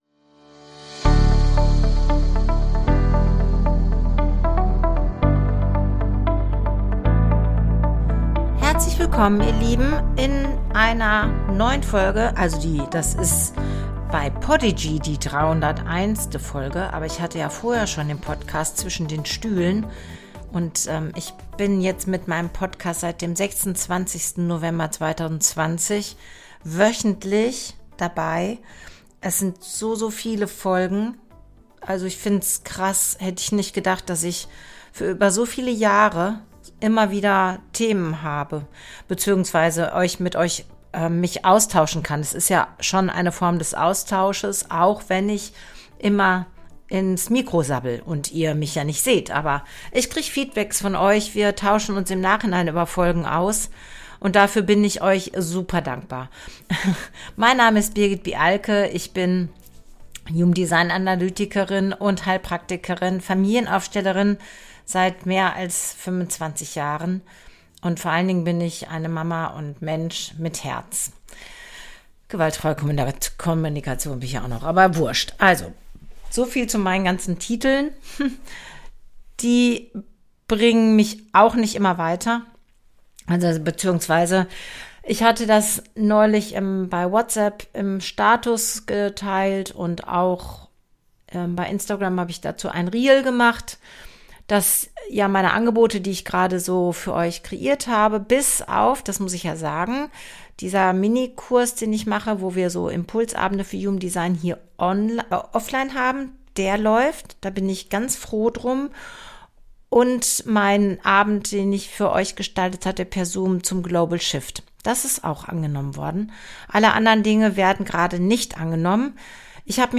Am Ende erwartet dich wieder ein beschwingtes Lied – zum Mitschwingen und Erinnern: Du bist genau richtig, so wie du bist.